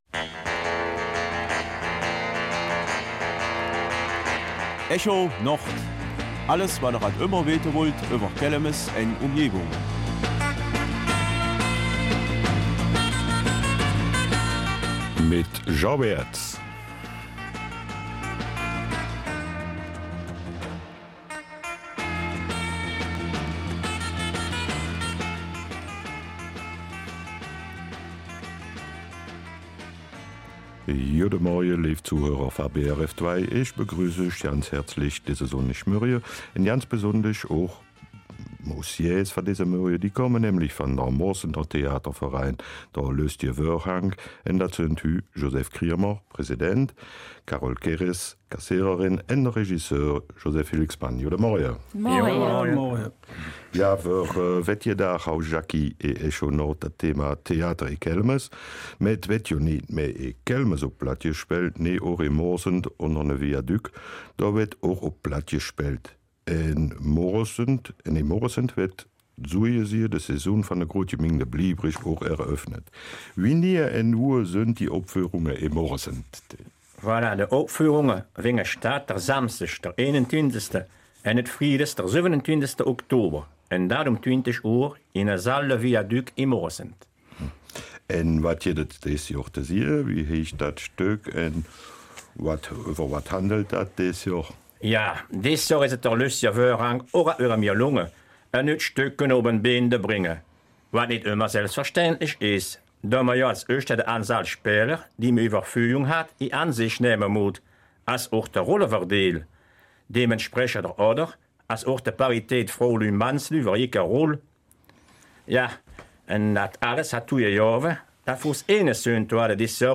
Seit 28 Jahren gibt es in Moresnet den Theaterverein ''Lösteje Vörhang''. Als Studiogäste